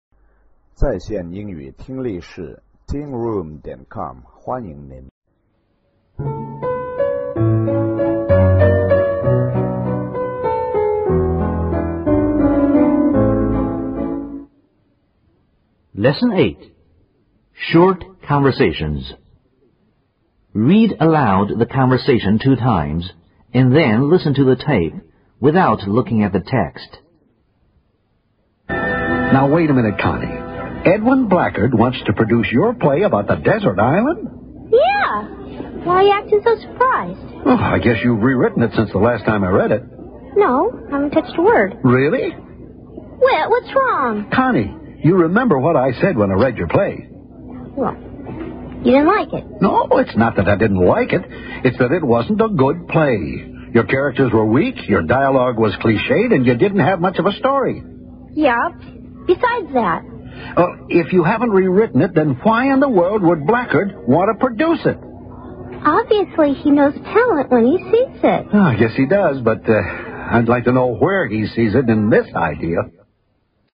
王强口语 第一册 Short conversation 008 听力文件下载—在线英语听力室